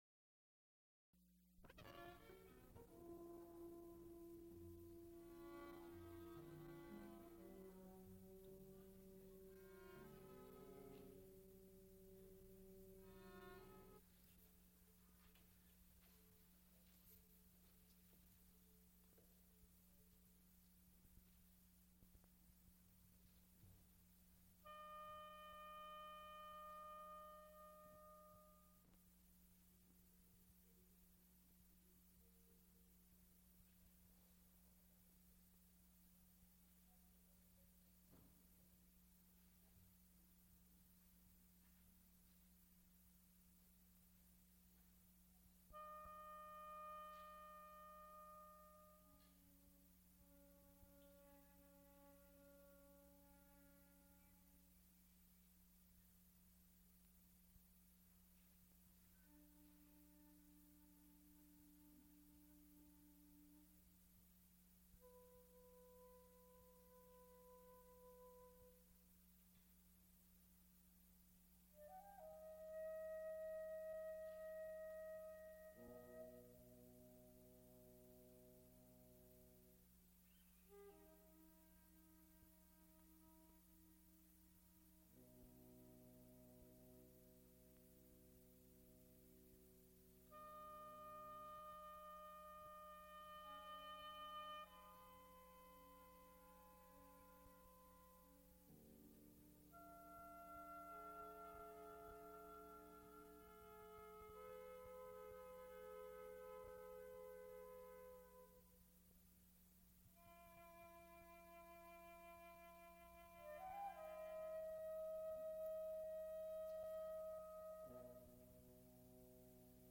Recorded live April 12, 1977, Frick Fine Arts Auditorium, University of Pittsburgh.
musical performances